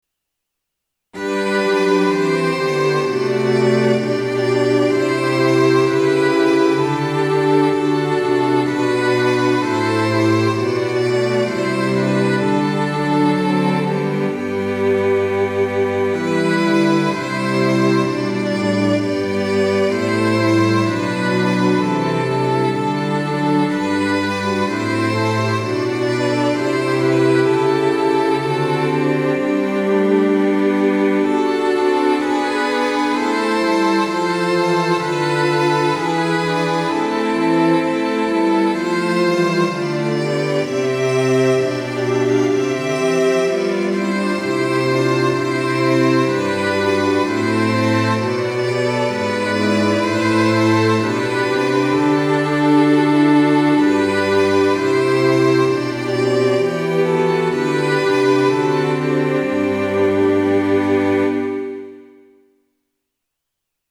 Sample Sound ：MIDI⇒MP3　Version A.1.6
Tonality：G (♯)　Tempo：Quarter note = 64